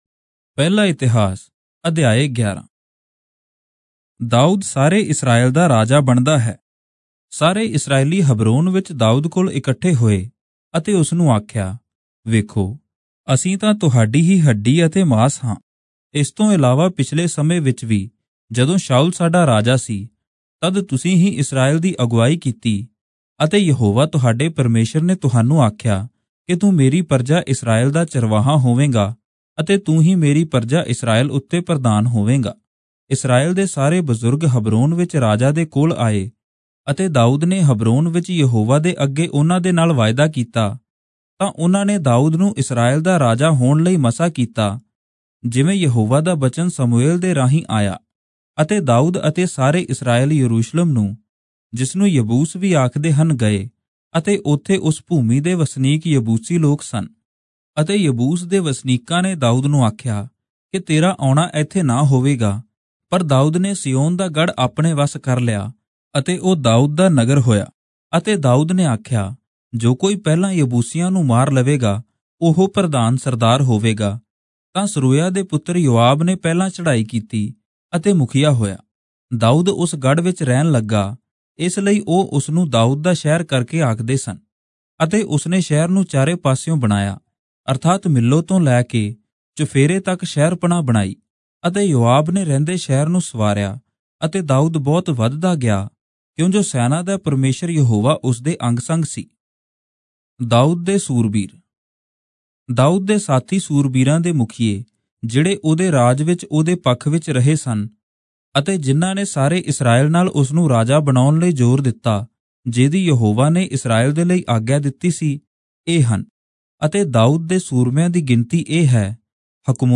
Punjabi Audio Bible - 1-Chronicles 11 in Irvpa bible version